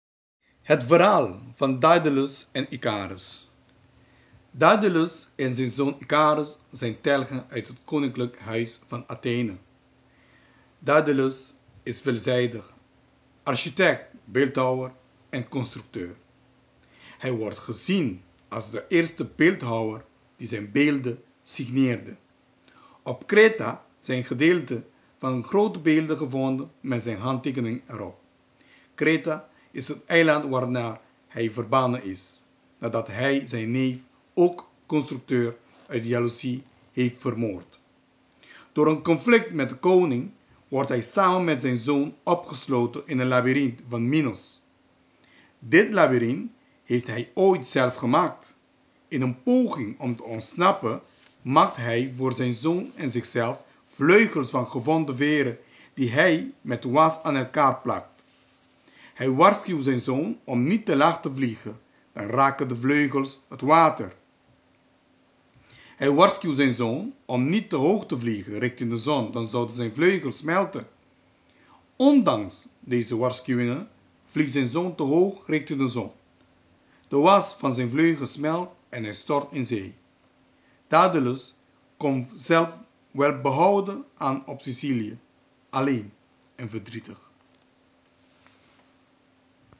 Het verhaal verteld..nog een keer